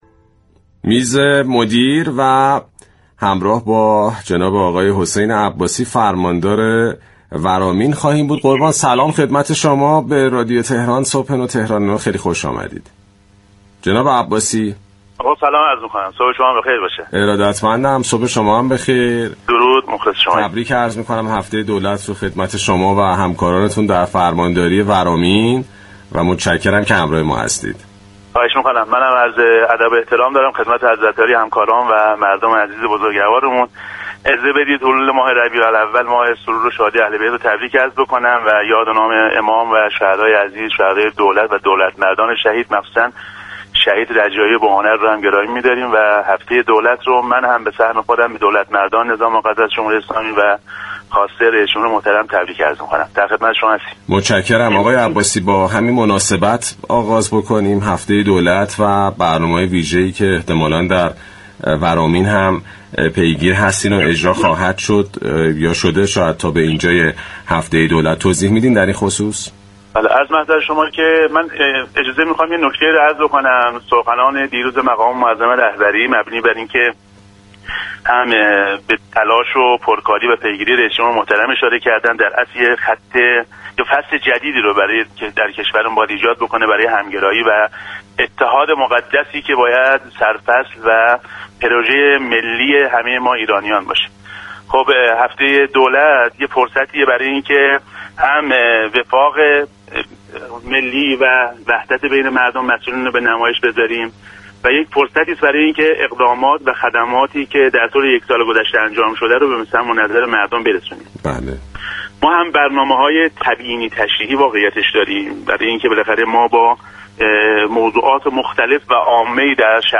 فرماندار ورامین در گفتگو با رادیو تهران اعلام كرد: همزمان با هفته دولت، 39 پروژه عمرانی، خدماتی و زیربنایی با اعتباری بالغ بر 729 میلیارد تومان در شهرستان ورامین افتتاح یا كلنگ‌زنی خواهد شد.